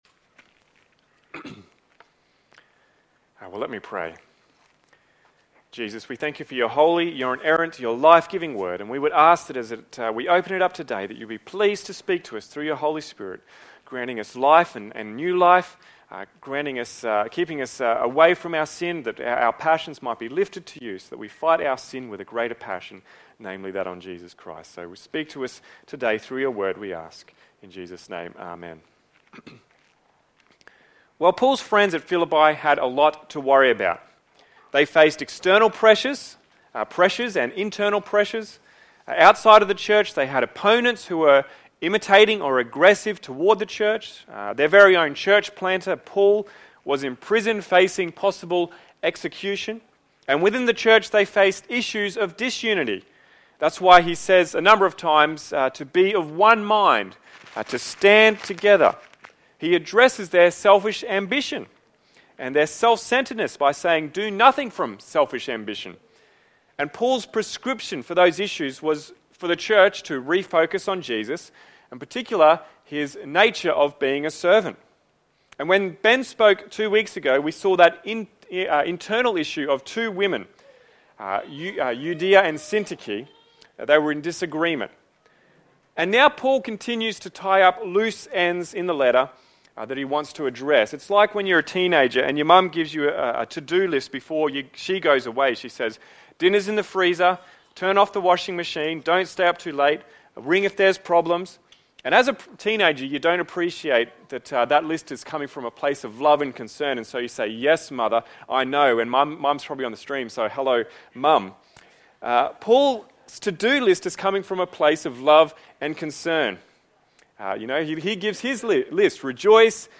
Sermons | Mount Isa Baptist Church